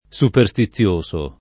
superstizioso [ S uper S ti ZZL1S o ] agg.